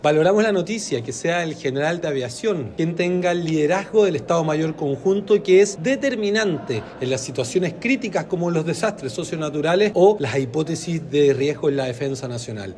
El diputado del Frente Amplio, e integrante de la comisión de Defensa de la Cámara, Jorge Brito, valoró el nombramiento.